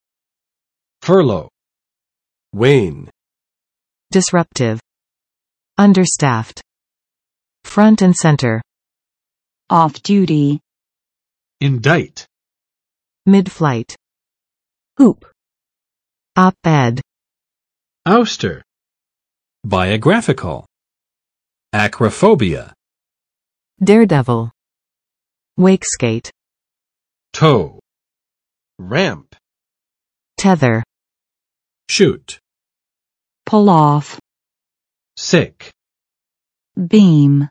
[ˋfɝlo] v.（暂时）解雇